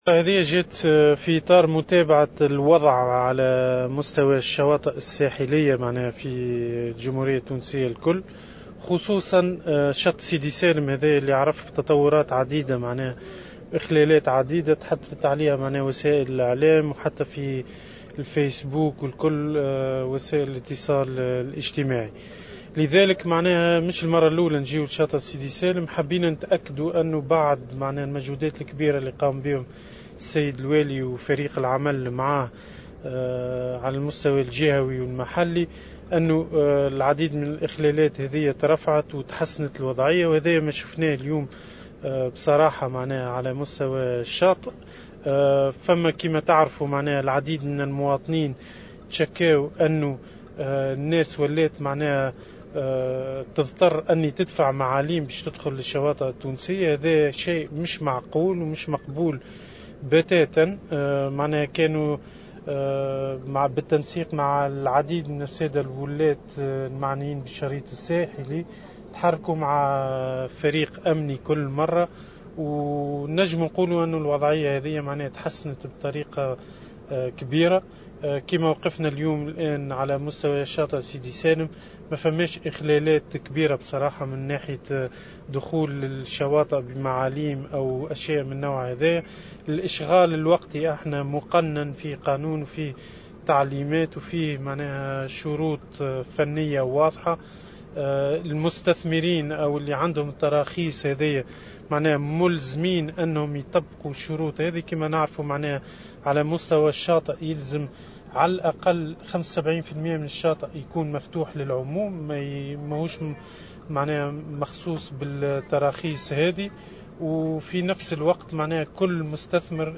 وأضاف الوزير على هامش الزيارة التي أداها اليوم لشاطئ سيدي سالم ببنزرت أن الحملة تهدف للتصدي إلى كل أشكال الاعتداء على الشريط الساحلي من بناءات عشوائية و عملية الاشغال الوقتي للملك العمومي البحري.
وزير البيئة